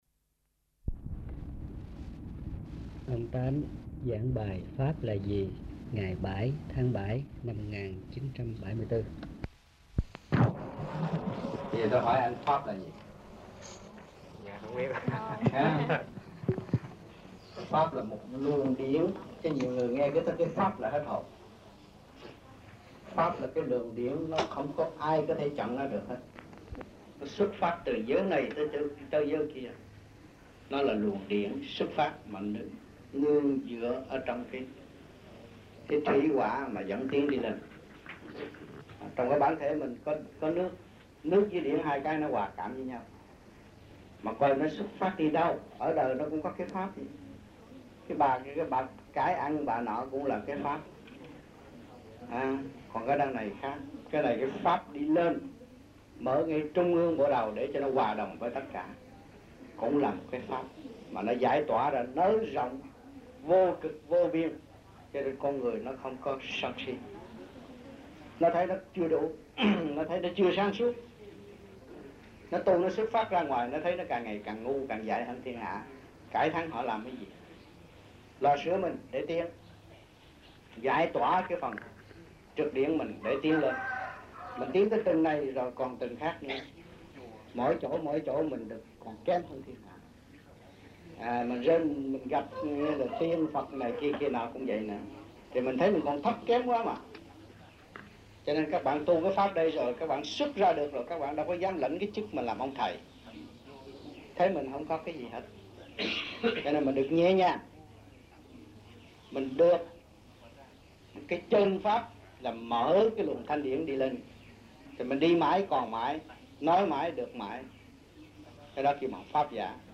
Việt Nam Trong dịp : Sinh hoạt thiền đường >> wide display >> Downloads